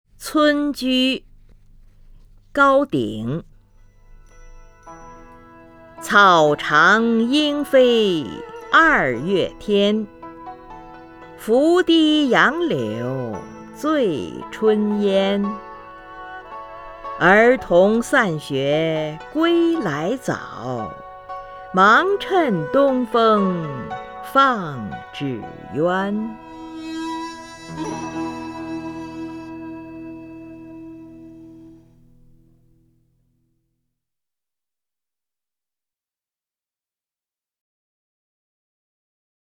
名家朗诵欣赏